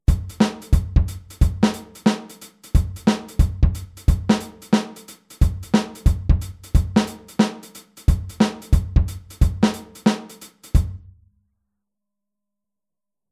Der Handsatz beim Shufflen
Groove06-24tel.mp3